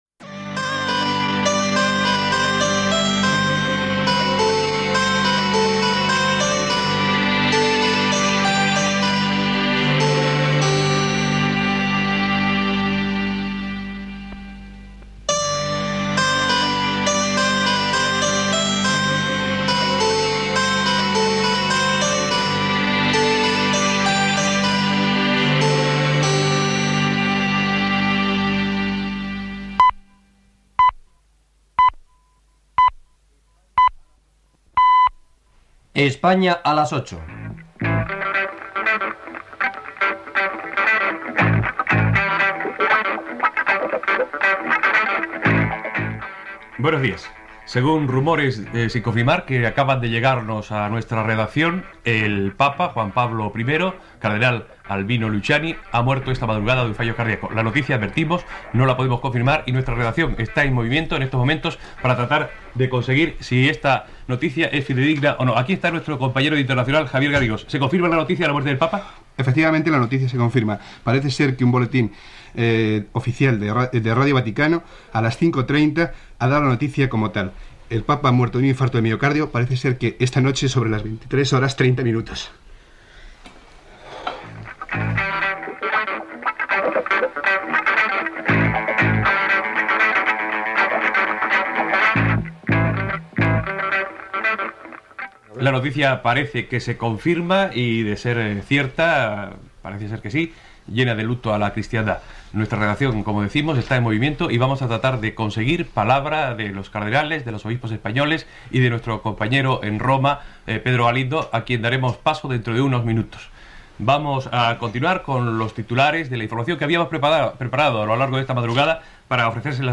Sintonia de la ràdio, senyals horaris, identificació del programa
Gènere radiofònic Informatiu